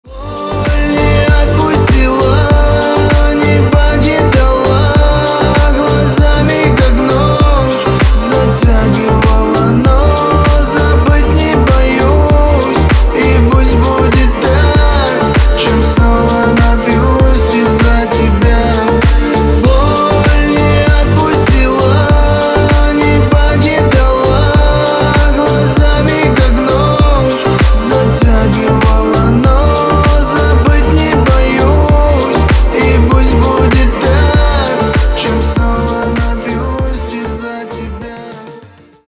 поп
восточные , грустные